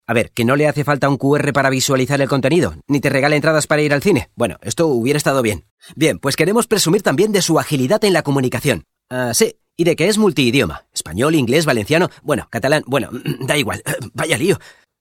Excelente dicción, vocalización y sonido brillante.
Utilizamos micrófonía Neuman U87 y Audio Technica 4050/CM5.
Sprechprobe: eLearning (Muttersprache):